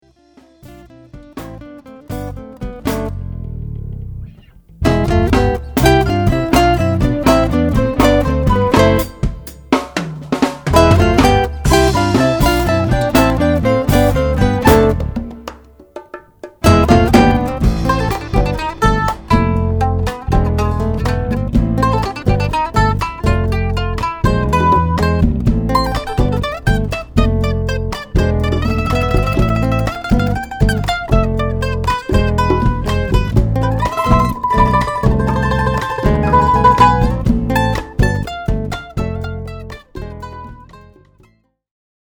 and contemporary jazz.
His nylon string guitar sings over original
hypnotic grooves, complimenting many venues